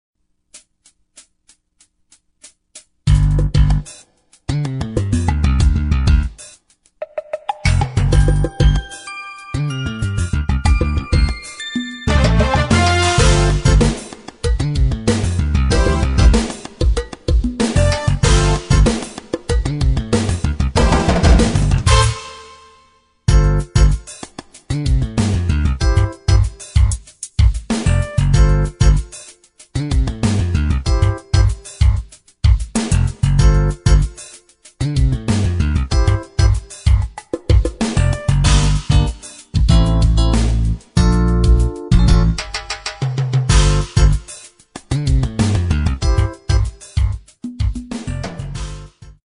Key of A minor
Backing track only.